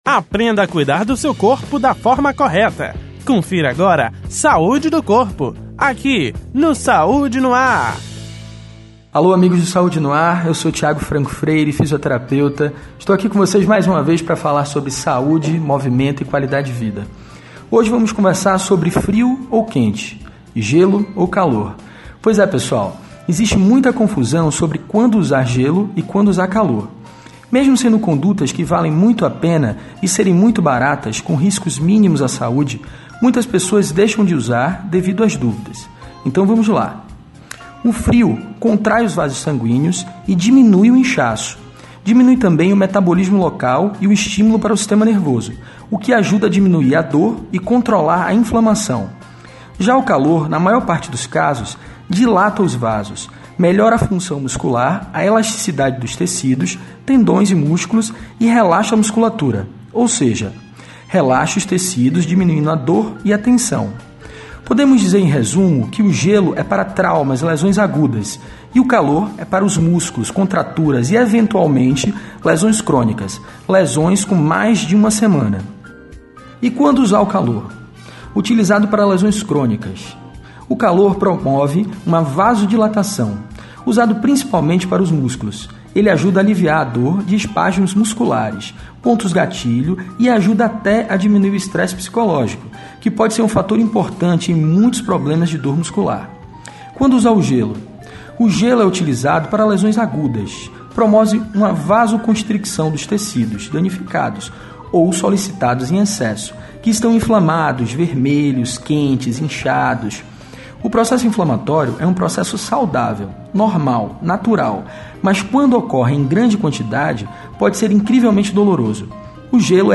Rede Excelsior de Comunicação: AM 840, FM 106.01, Recôncavo AM 1460 e Rádio Saúde no ar / Web